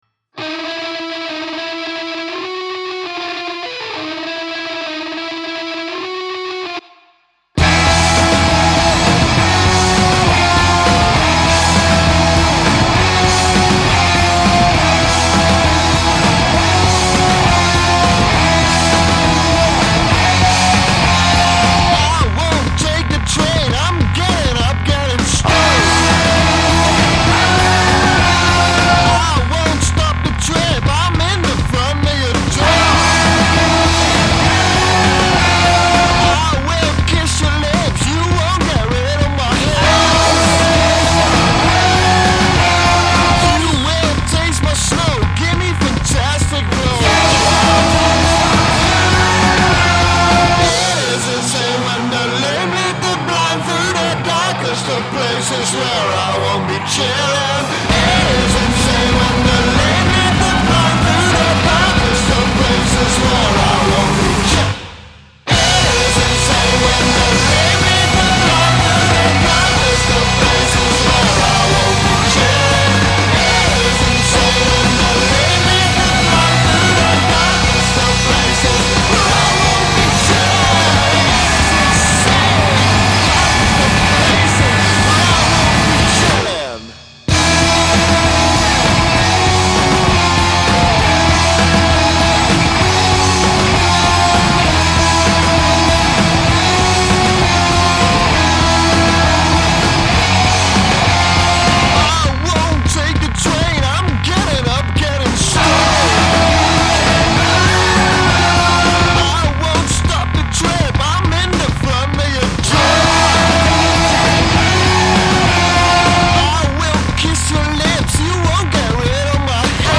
‘Heftig’ is een understatement